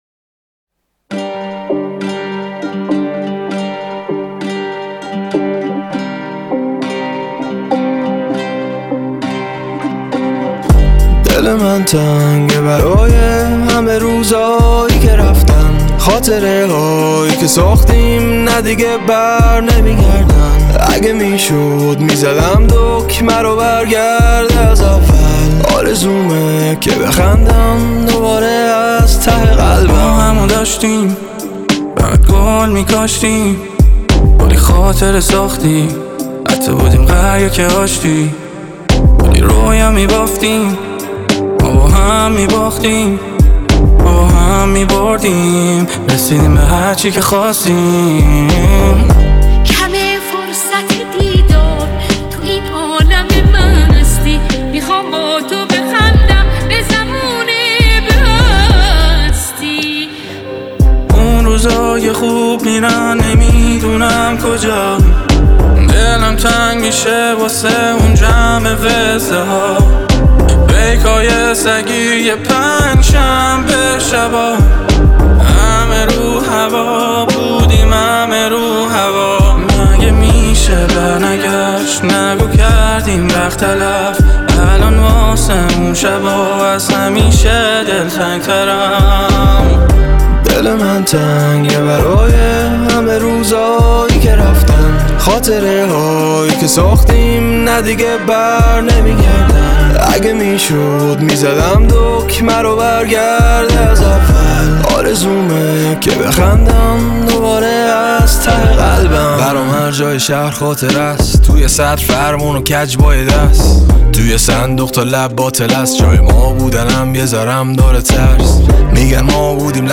نگران (ریمیکس)